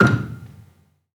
Gamelan Sound Bank
Gambang-F5-f.wav